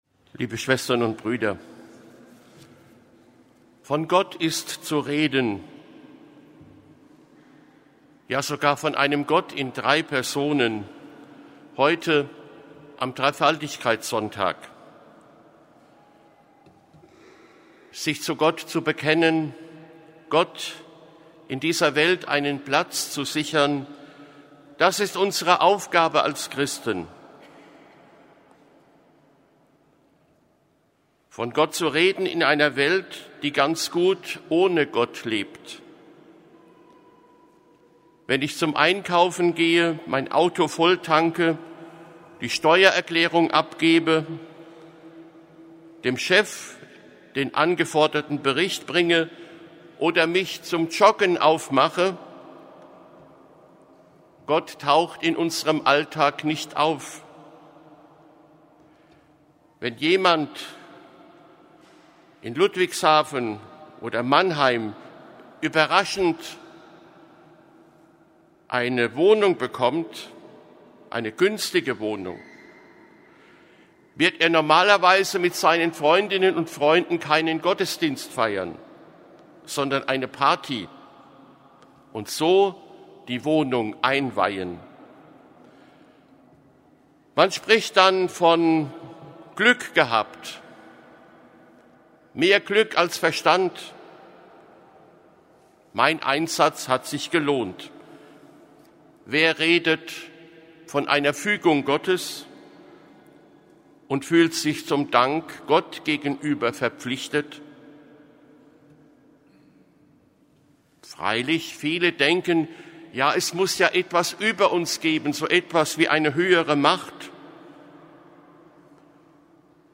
Weihbischof Otto Georgens: Predigten und Ansprachen | Bistum Speyer
26.05.2024 Predigt Wallfahrt der muttersprachlichen Gemeinden
Predigt_Wallfahrt_muttersprachliche_Gemeinden_2024.mp3